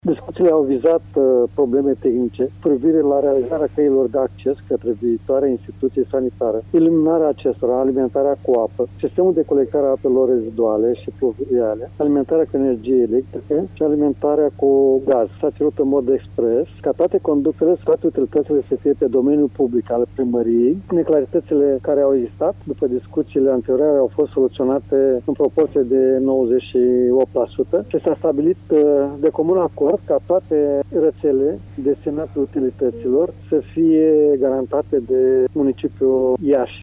Preşedintele Consiliului Judeţean Iaşi, Maricel Popa, a precizat că strada de acces va deservi doar spitalul de urgenţe, iar toate proiectele reţelelor de utilităţi vor fi realizate pe domeniul public al municipiului Iaşi.